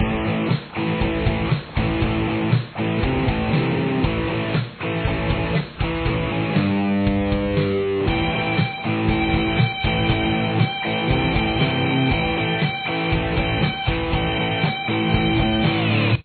Here are just the guitars: